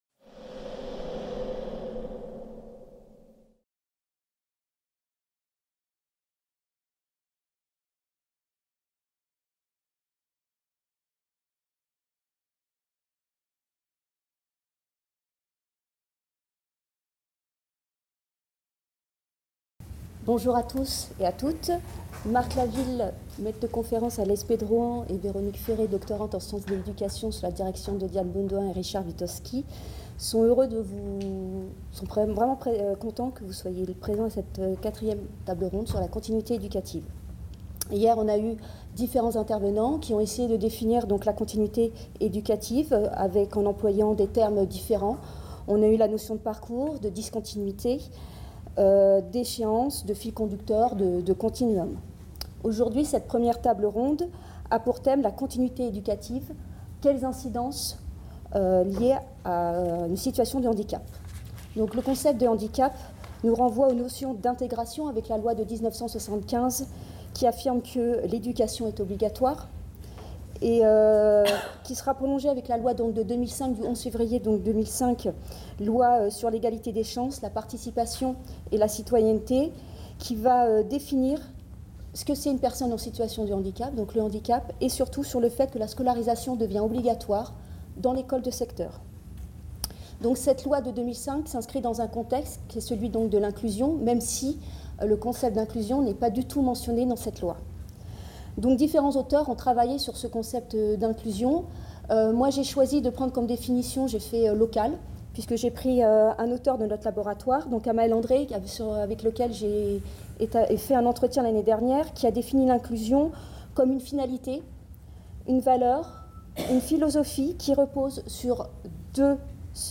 CIRNEF18 | 06 - Table ronde D : quelles incidences liées à une situation de handicap ? | Canal U